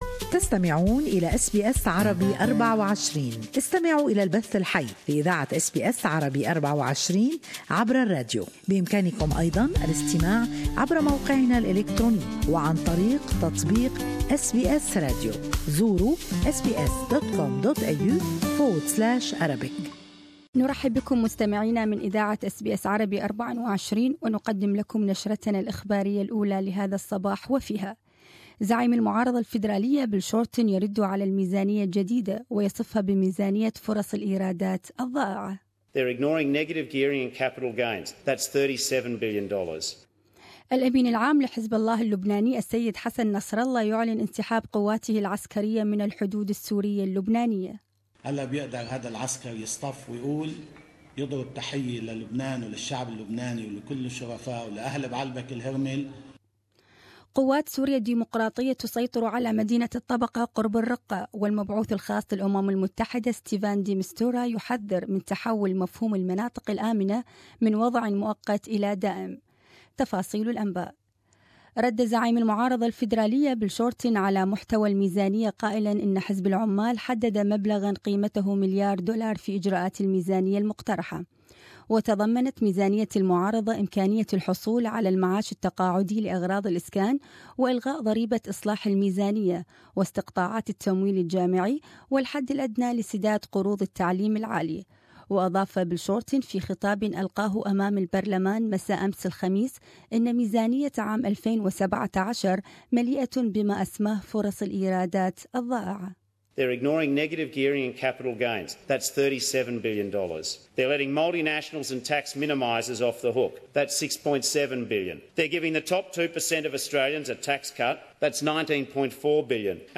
Morning news bulletin with latest Australian and world news.